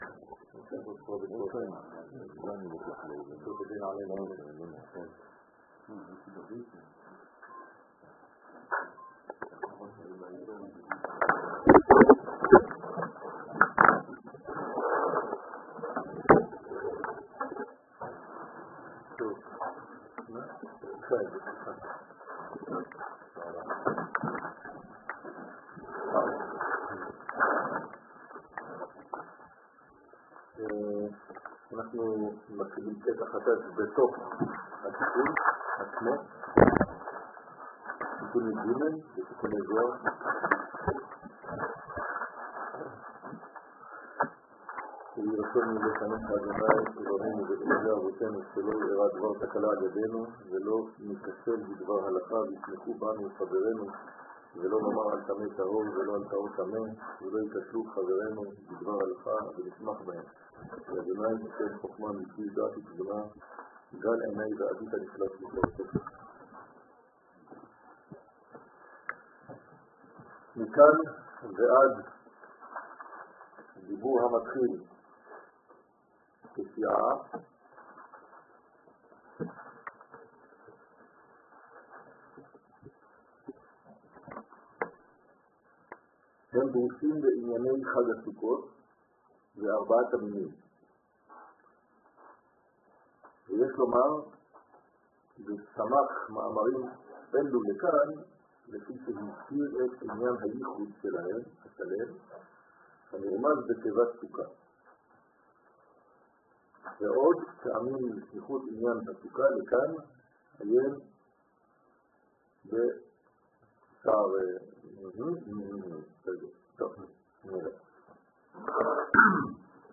שיעורים